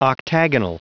Prononciation du mot octagonal en anglais (fichier audio)
Prononciation du mot : octagonal
octagonal.wav